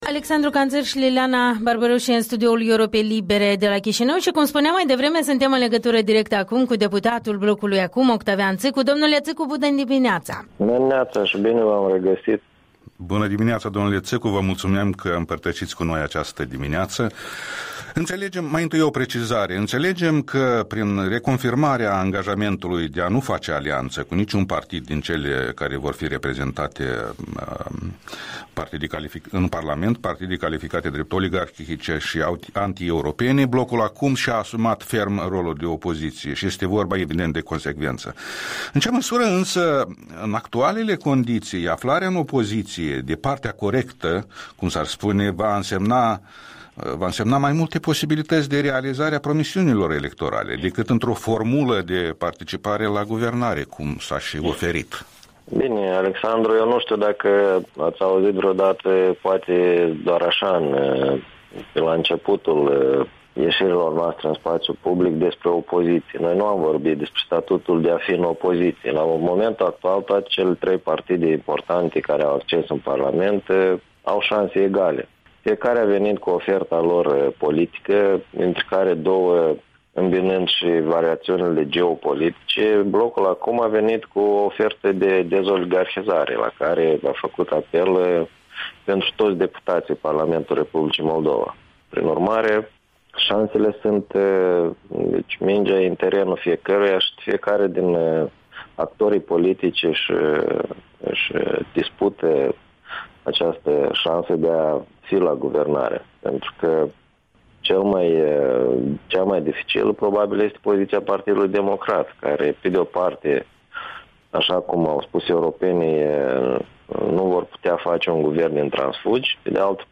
Interviul dimineții cu noul deputat al Blocului ACUM despre măsuri „neordinare” și lustrația în justiție.